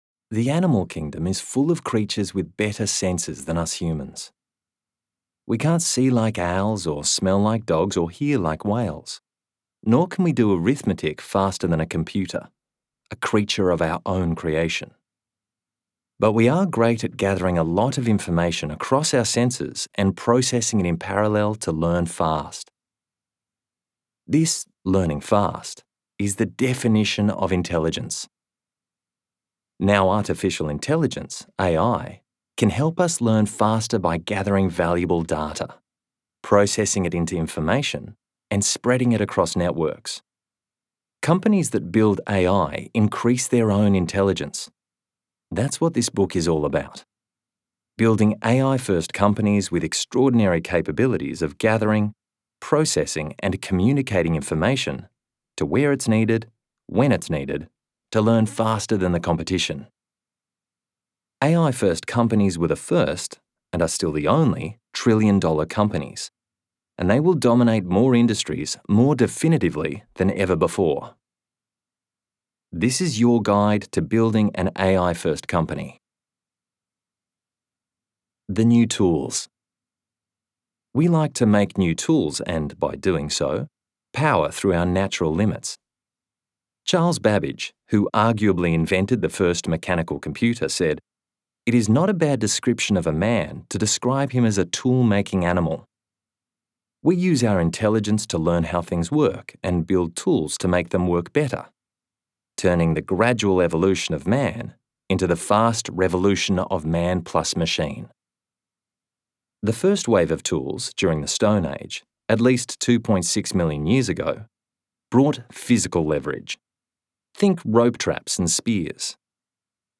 ai-first-company-audiobook-sample.mp3